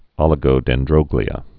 (ŏlĭ-gō-dĕn-drŏglē-ə, ōlĭ-)